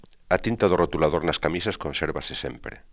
a tínta Do rrotulaDór nas kamísas koNsÉrBase sÉmpre.